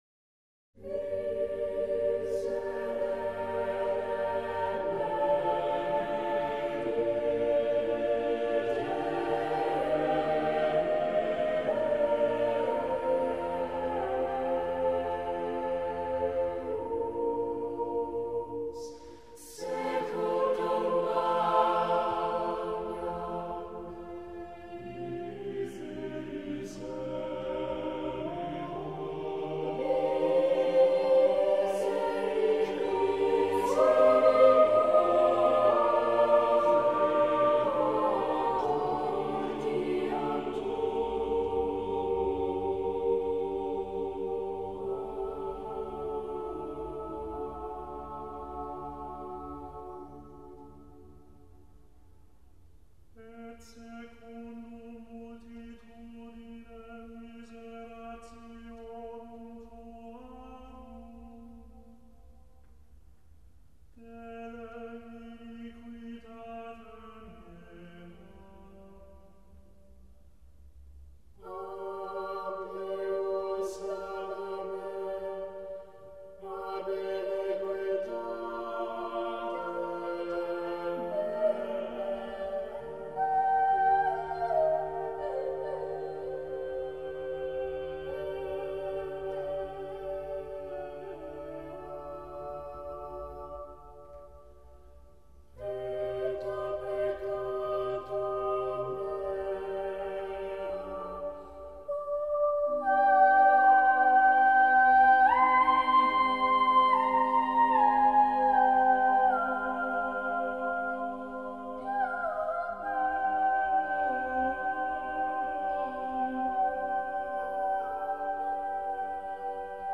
A jestě jedna nová píseň bohužel jenom část.